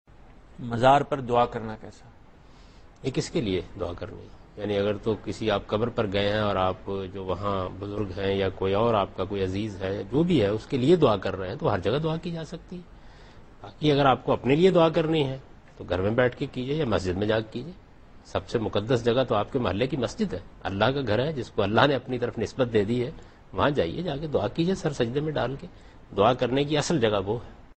Category: TV Programs / Dunya News / Deen-o-Daanish /
Javed Ahmad Ghamidi answers a question regarding "Praying on Shrines" in Dunya Tv's Program Deen o Daanish.